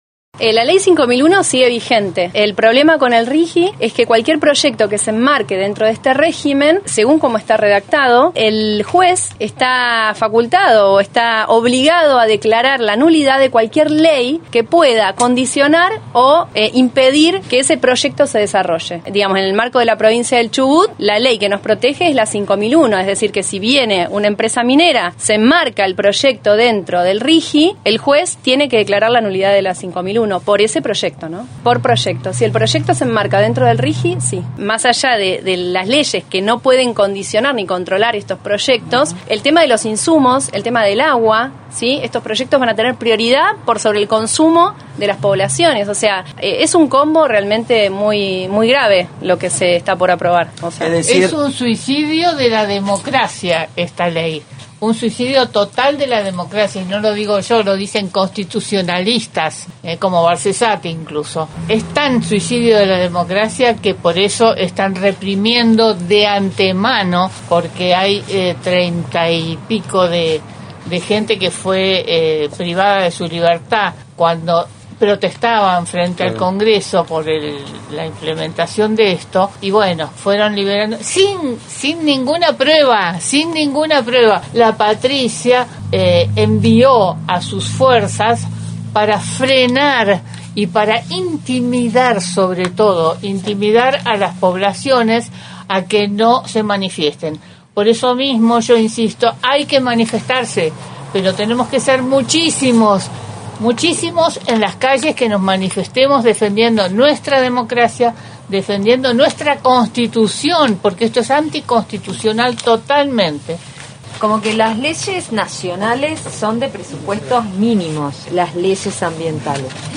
Luego de la lectura del documento, las vecinas fueron consultadas sobre la vigencia de la Lay 5001 en Chubut, ante la aprobación en el Senado de la Nación de la Ley de Bases.